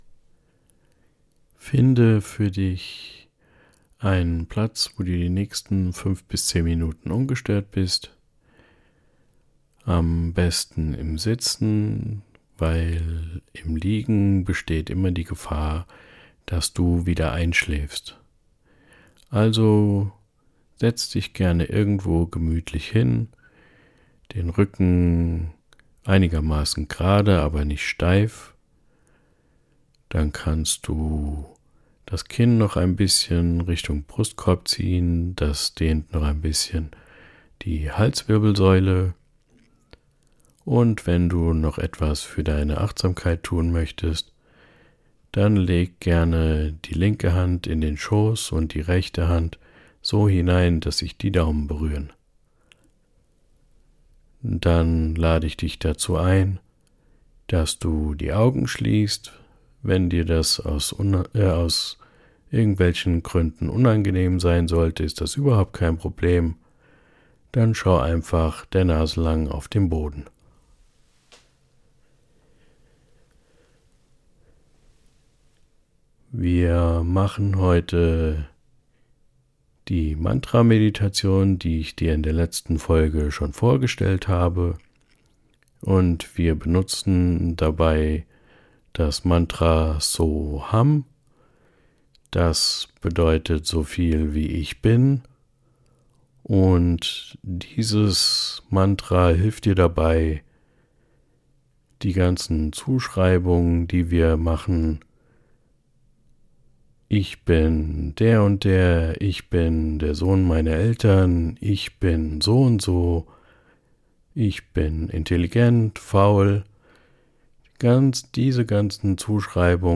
Geführte Mantra-Meditation